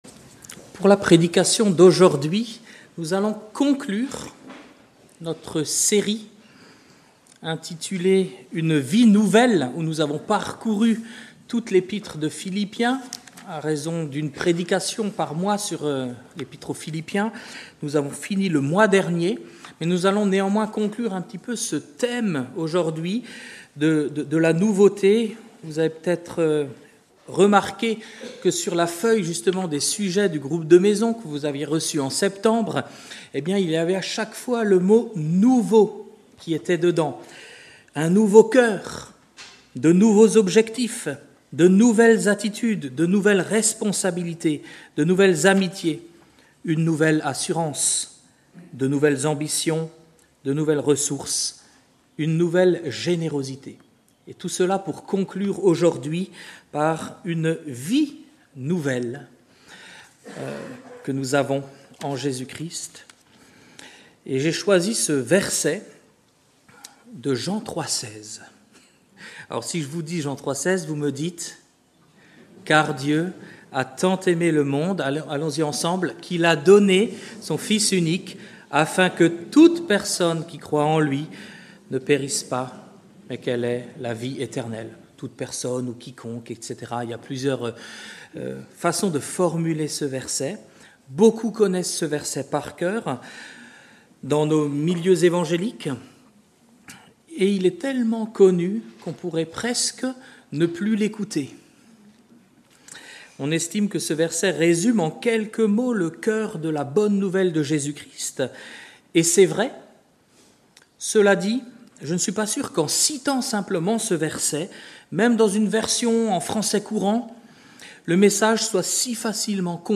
Culte du dimanche 8 juin 2025 – Église de La Bonne Nouvelle